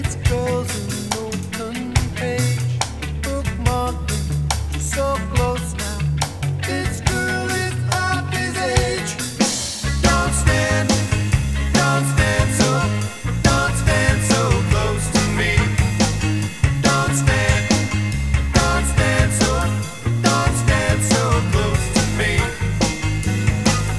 One Semitone Down Pop (1980s) 3:42 Buy £1.50